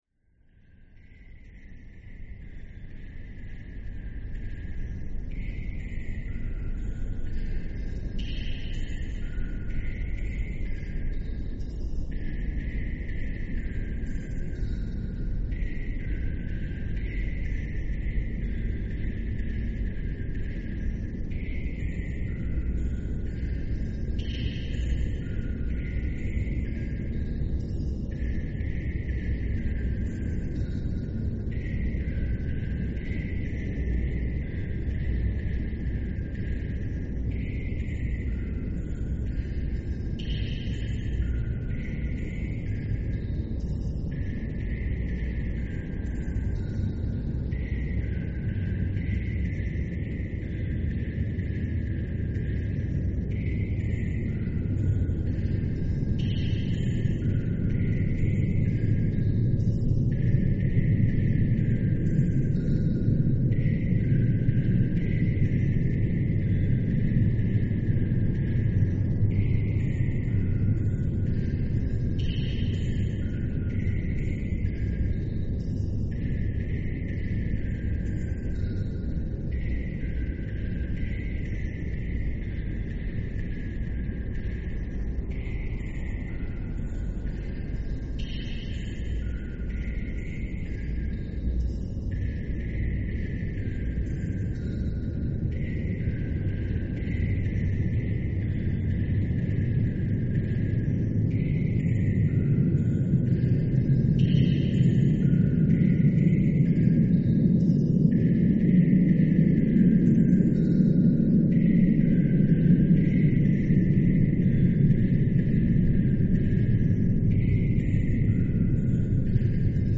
ambient_location.mp3